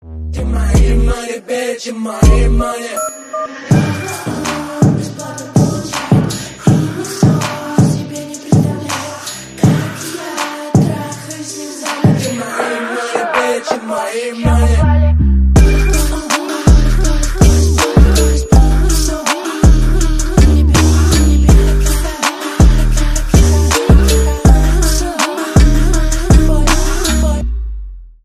Ремикс # Slowed + Reverb
клубные # громкие